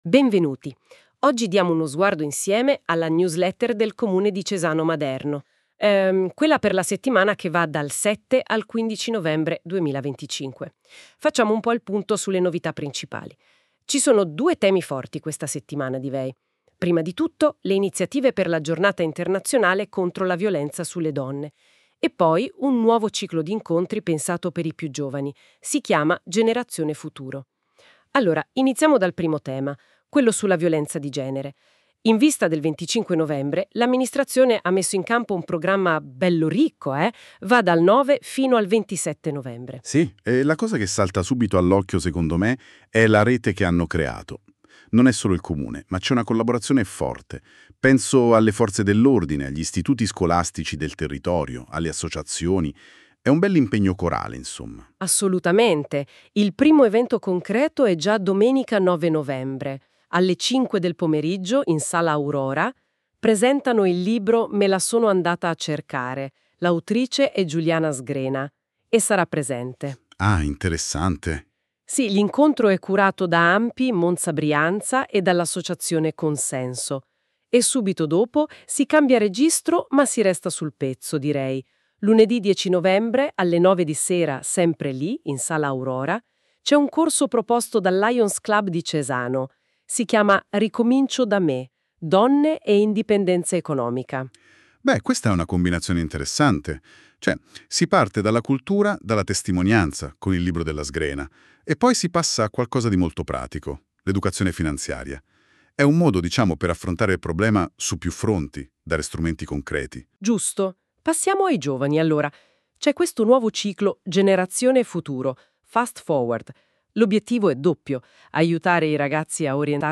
Il podcast è stato realizzato con l’ausilio dell’IA, potrebbe contenere parziali errori nelle pronunce o in alcune definizioni.